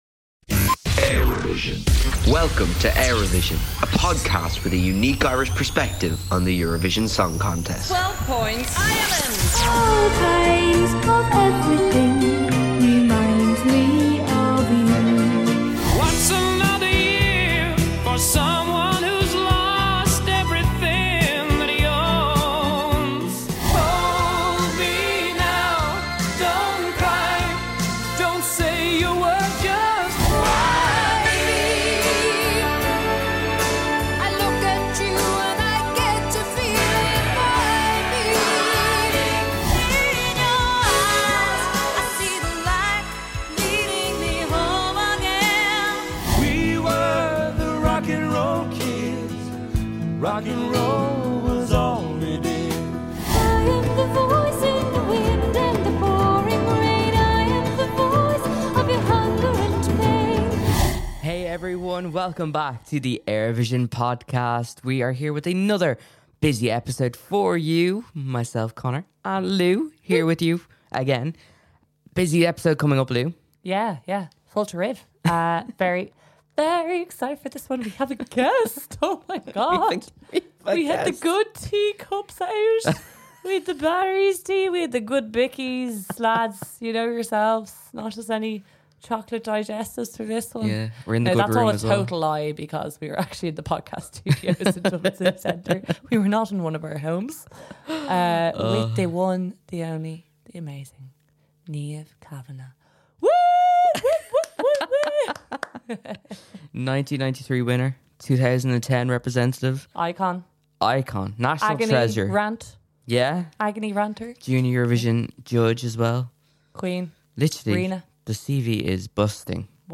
Our chat with Niamh Kavanagh!